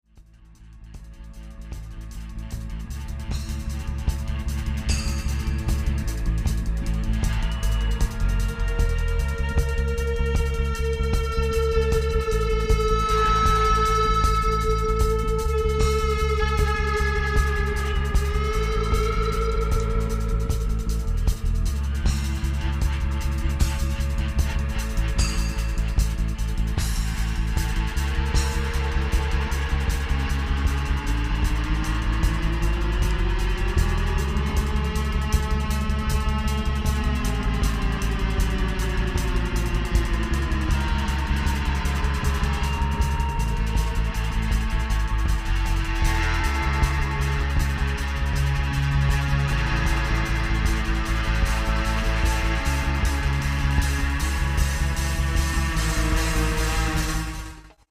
(alternative rock), 2003